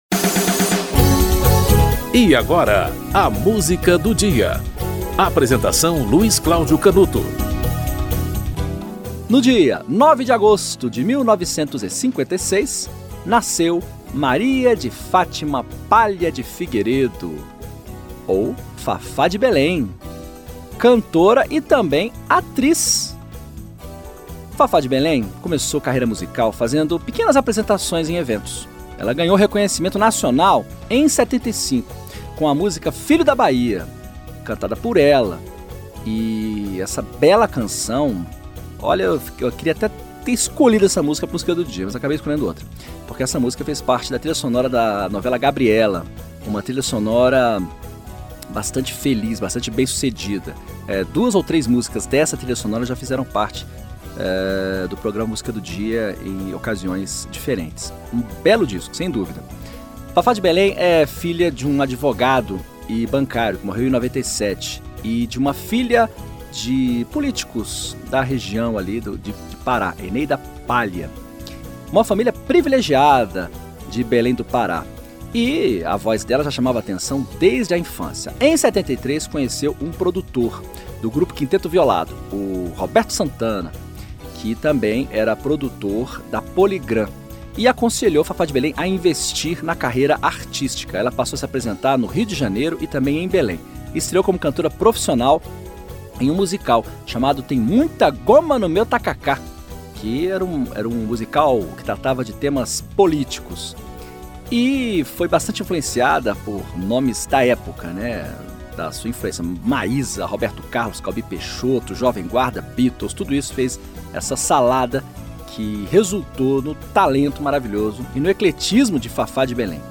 Produção e apresentação